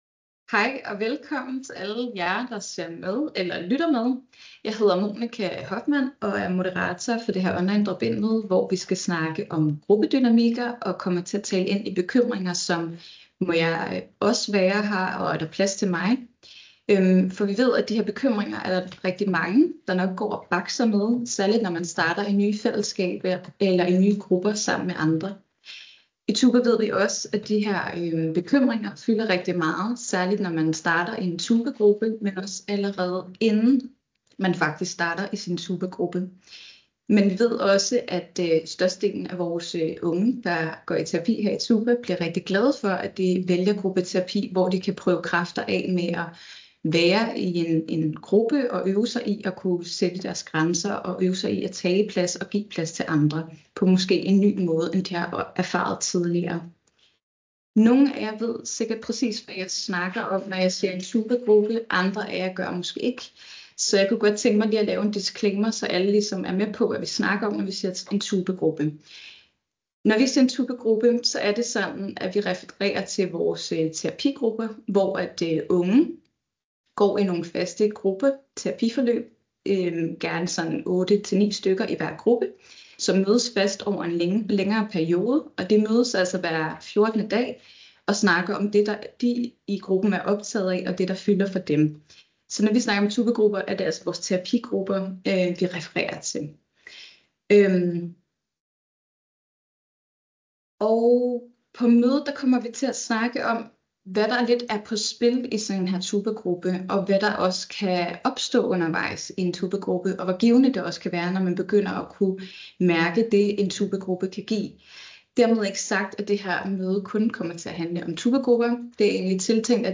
Online drop-in møder
På dette drop-in møde debatterer og vender vi nogle af de tanker, spørgsmål og dilemmaer omkring fællesskaber og grupper, der fylder, og som I har delt med os via vores sociale medier eller taget med på selve aftenen.
Mødet er afholdt og optaget d. 3. april 2025.